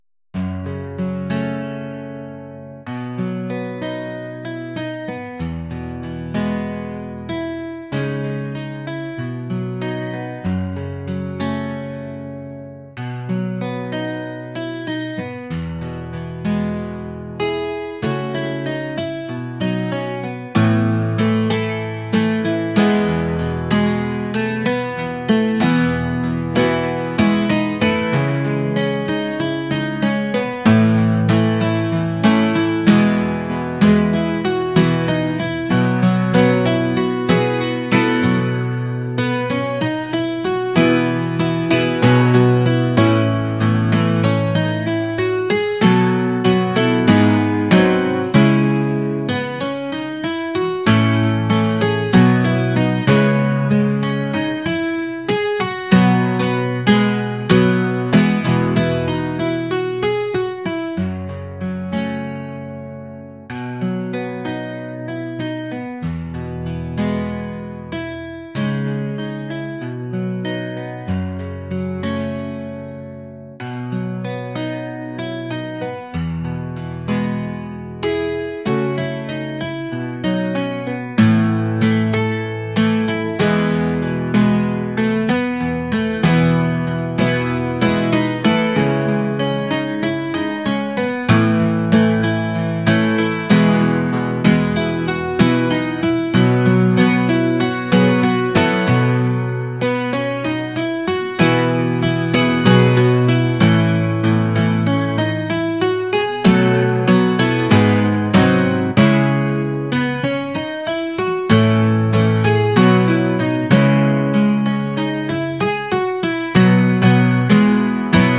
acoustic | folk | laid-back